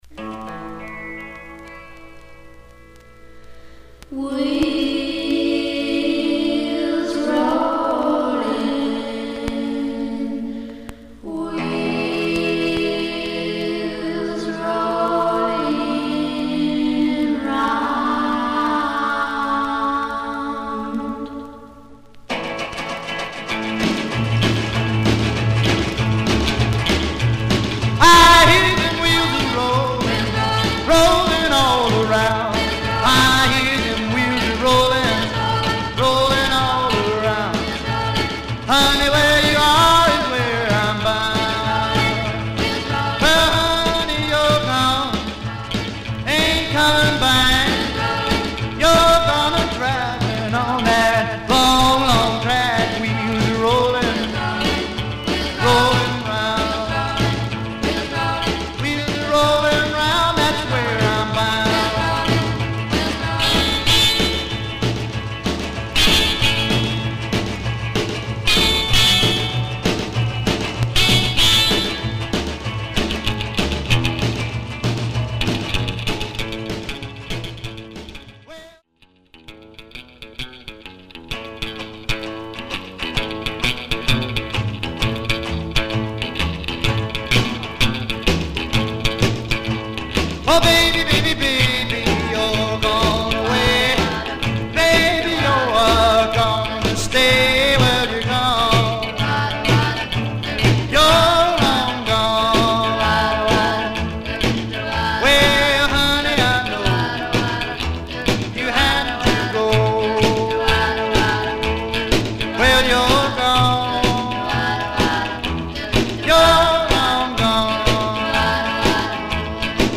Some surface noise/wear Stereo/mono Mono
Rockabilly